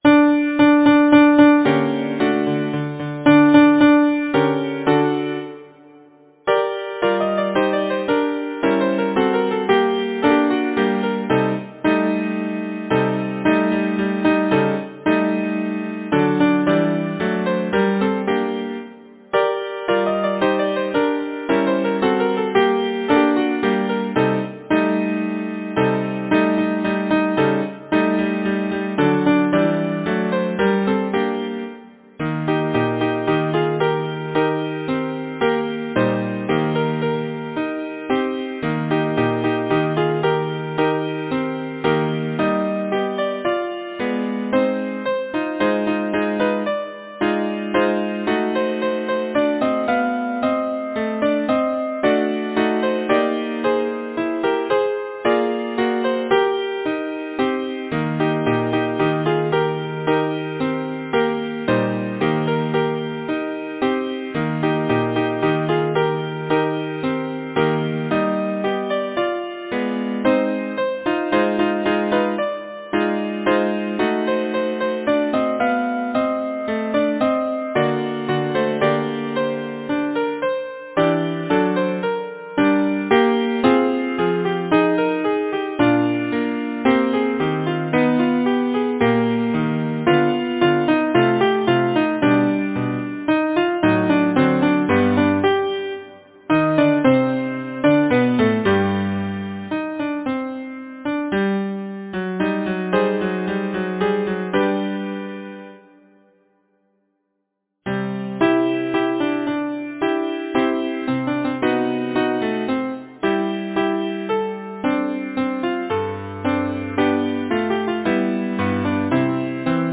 Title: The gypsy grove Composer: Sebastián Yradier Arranger: Harvey Worthington Loomis Lyricist: Victor N. Pierpontcreate page Number of voices: 4vv Voicing: SATB, some S divisi Genre: Secular, Partsong
Language: English Instruments: A cappella